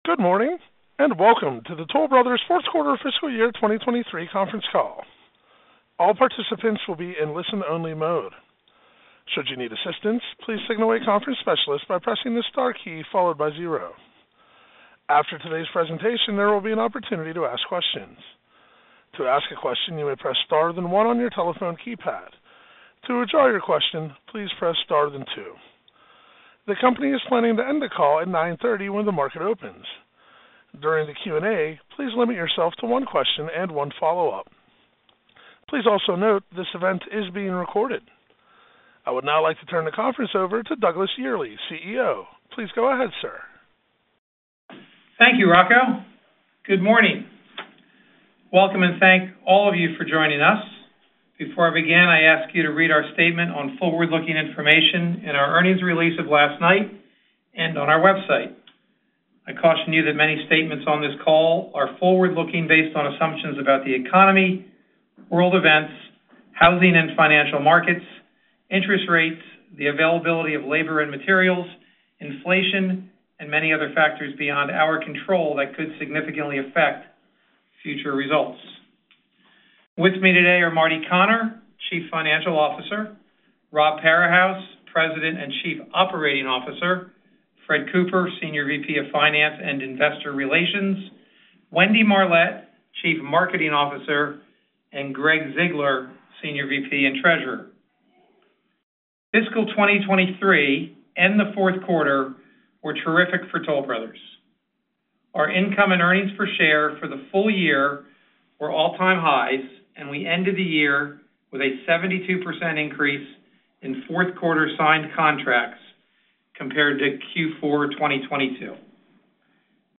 Click on a link below to listen to one of our Conference Calls.